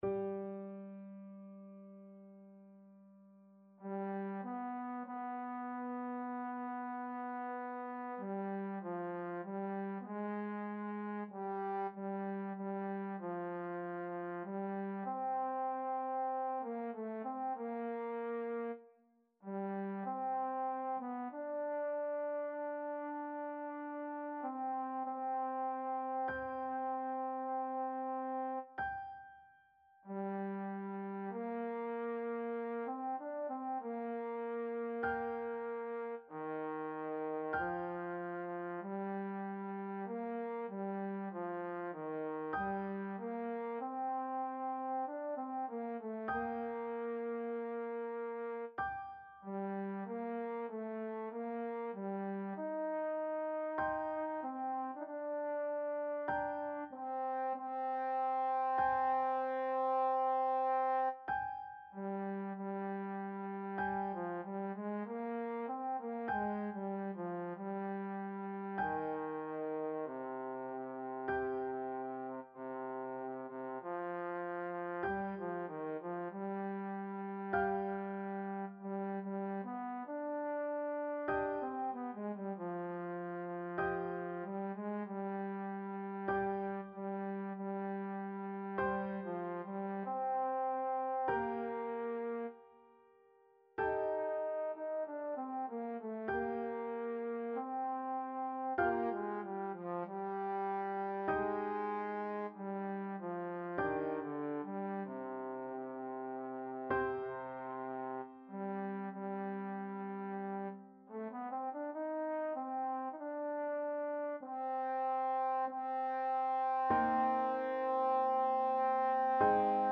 4/4 (View more 4/4 Music)
C4-G5
Lent =48
Classical (View more Classical Trombone Music)